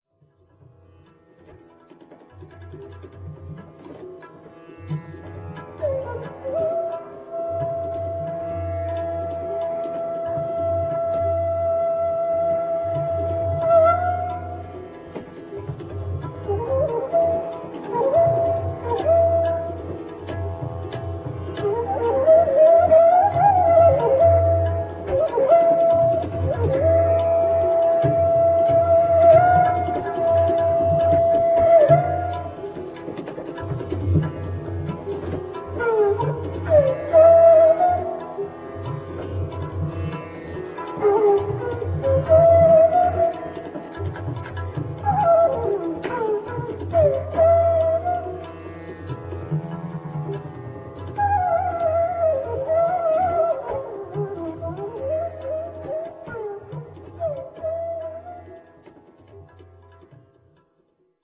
Вечная музыка природы
Бансури (бамбуковая флейта)